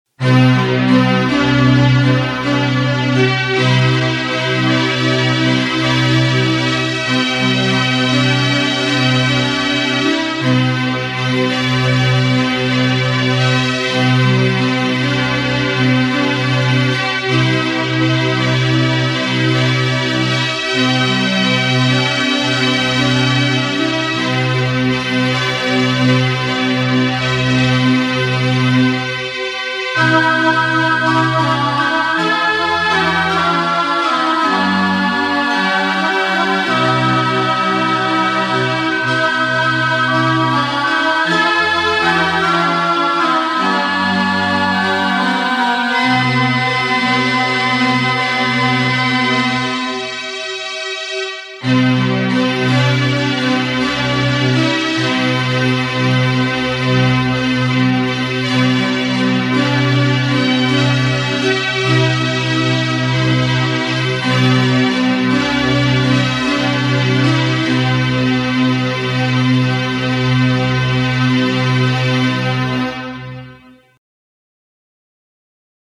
Školní hymna je slavnostní fanfára, která se hraje u slavnostních příležitostí jako je vyřazení žáků 9. tříd.